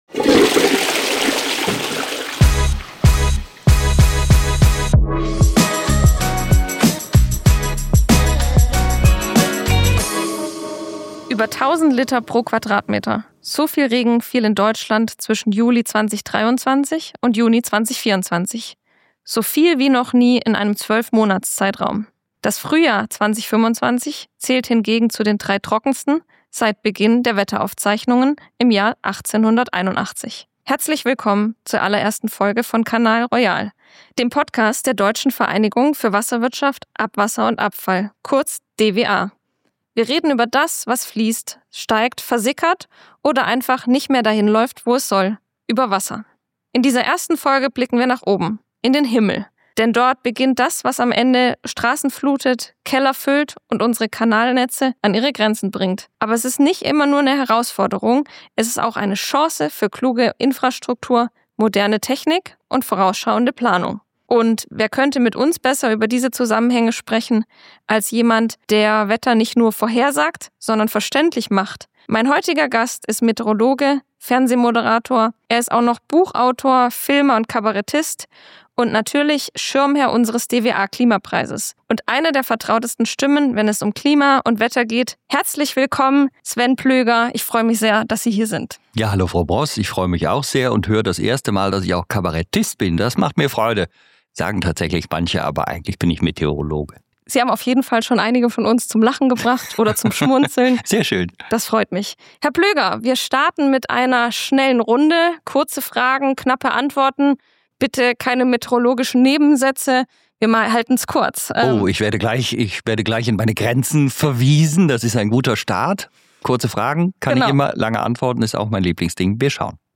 Warum gute Kommunikation Leben retten kann, was Politik und Gesellschaft jetzt tun müssen – und warum Fruchtgummis als Symbol für Rettung stehen. Ein Gespräch mit Tiefgang, Klartext und Weitblick.